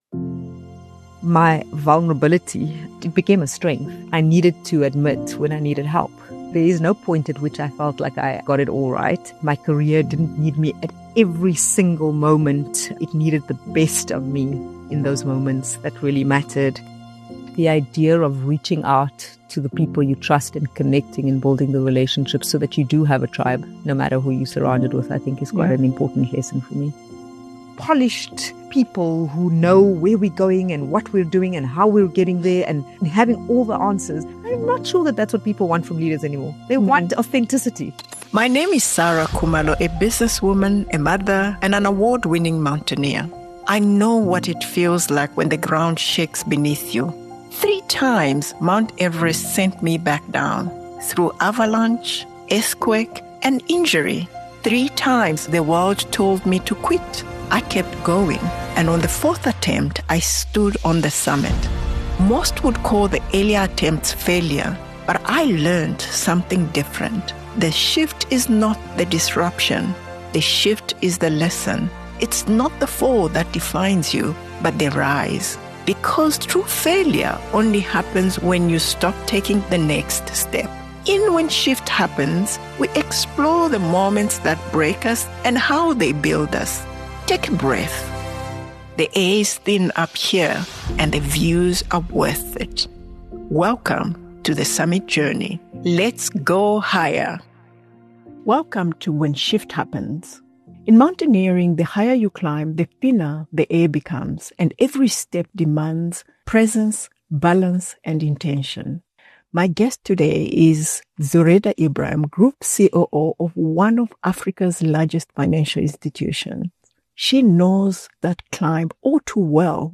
It’s a thoughtful conversation about perspective, pressure and the moments that quietly change how leaders see their work and their lives.